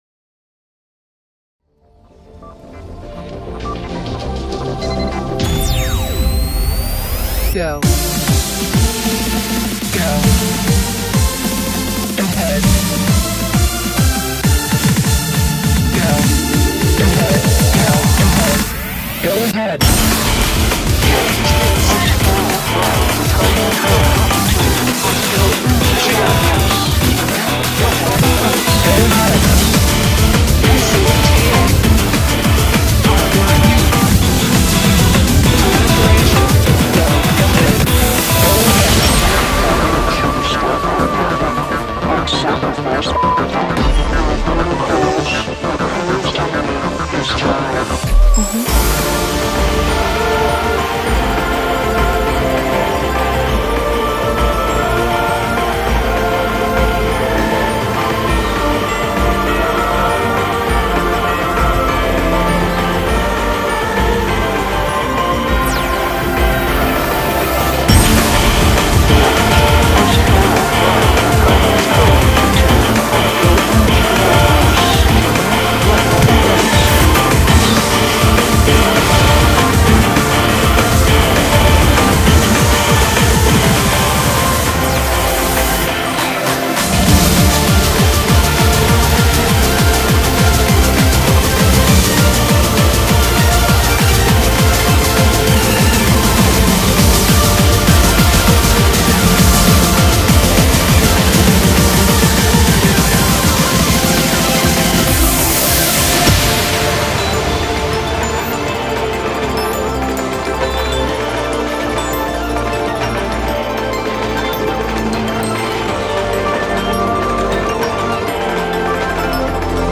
BPM50-200
Audio QualityPerfect (High Quality)
Note: Here's another IIDX mashup.